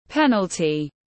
Phạt đền tiếng anh gọi là penalty, phiên âm tiếng anh đọc là /ˈpen.əl.ti/
Penalty /ˈpen.əl.ti/